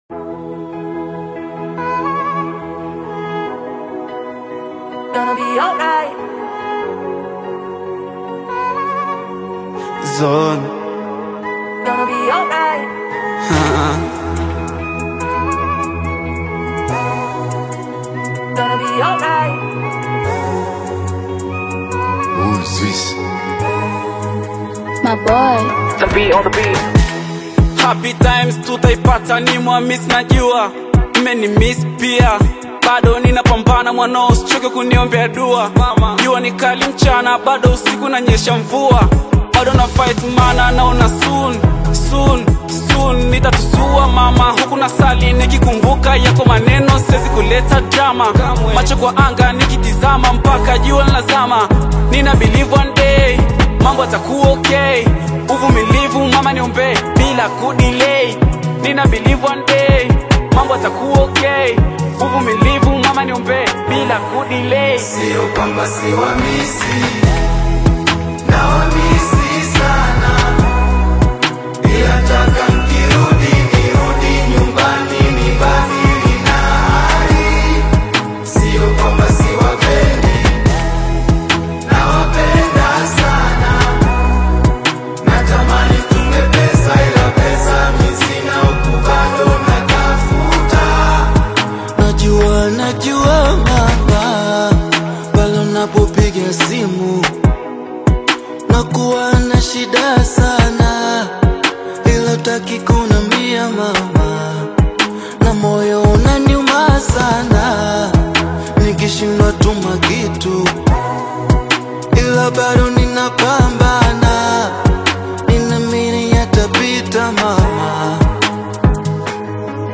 melodic Bongo Flava/Afro-Pop single
modern East African rhythms
smooth vocals and rhythmic production
Latest Bongo Flava, Afrobeat and more updates 🔥